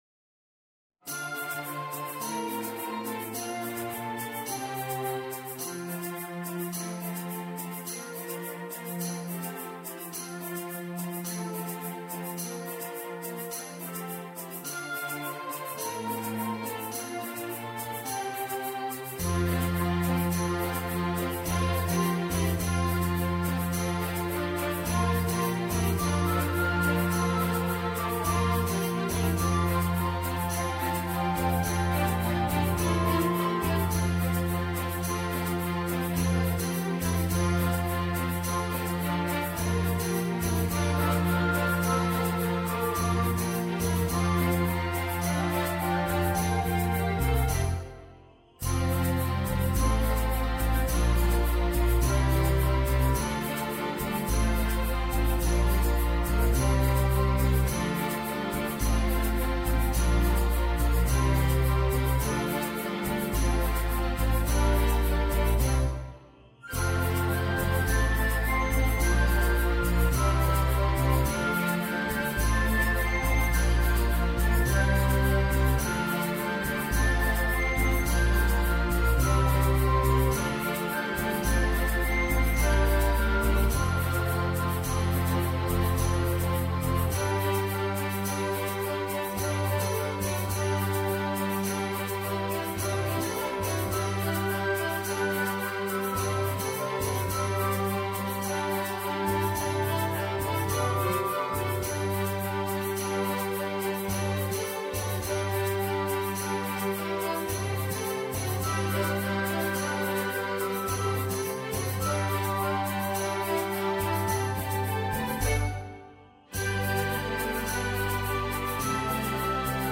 Road-Goes-On-Soprano-2.mp3